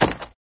woodstone3.ogg